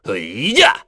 Lusikiel-Vox_Attack3.wav